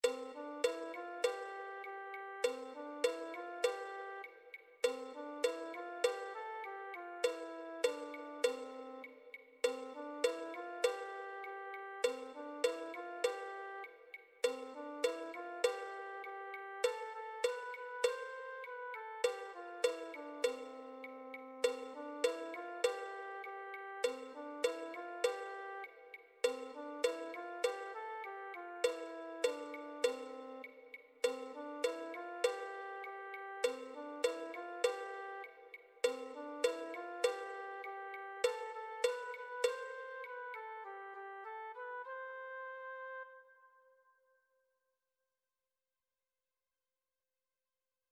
2.PERC.
La-escalerita-f-2.PERC_.mp3